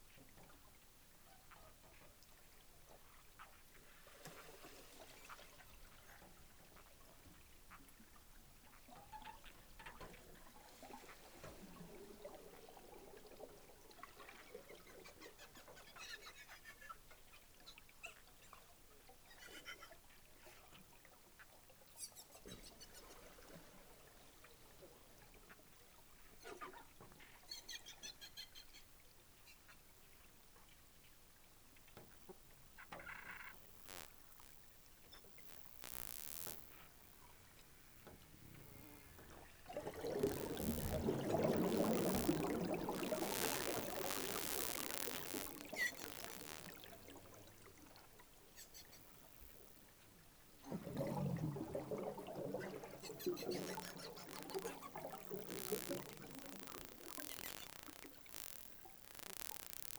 Inia geoffrensisPink river dolphin, Inia geoffrensis While in the Pacaya–Samiria National Reserve in the Peruvian Amazon, I had the opportunity to record pink river dolphin on a number of occassions.
Towards the end of the video you can hear and see bubbles that the dolphin blew under our drifting boat.
Note that much of the dolphin's sounds are above the human hearing range, but you can hear it if you playback at a slower speed.